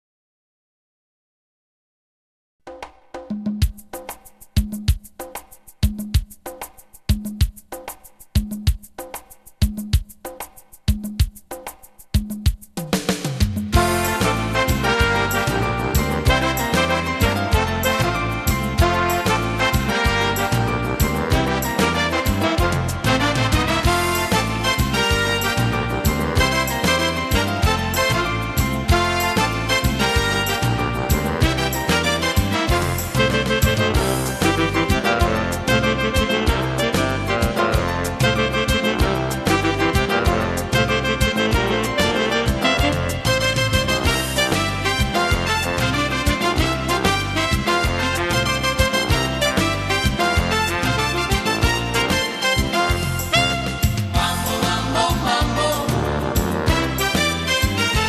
Mambo
12 brani per sax e orchestra.